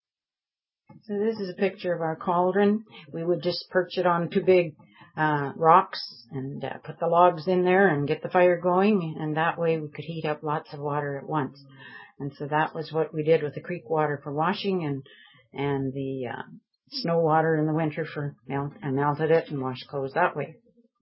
Heating Water - Sound Clip